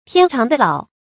天长地老 tiān cháng dì lǎo 成语解释 亦即天长地久。